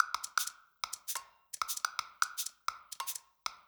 Index of /musicradar/uk-garage-samples/130bpm Lines n Loops/Beats
GA_PercE130-03.wav